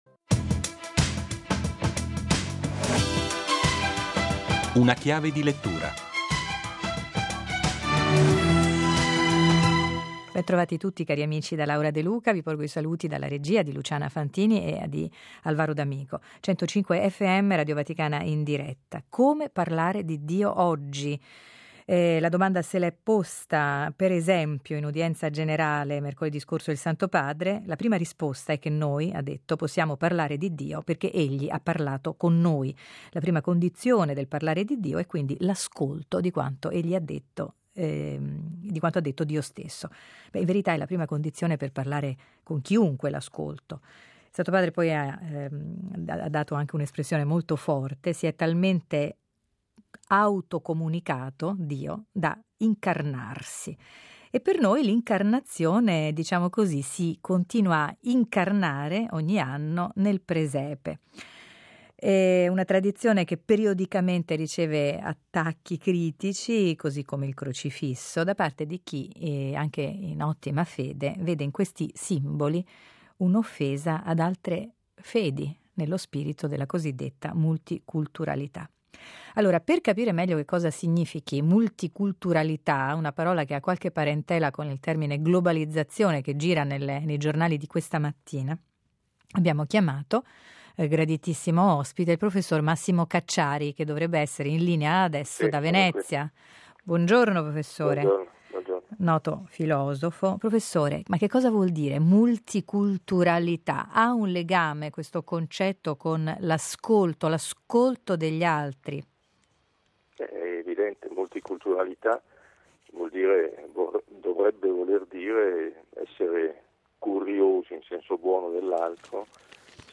Ne parliamo con il filosofo Massimo Cacciari.